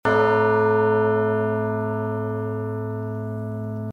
Левая половина звук Тубубелл (некое подобие колокольного звука, но это трубы...)
Хорошее качество звука.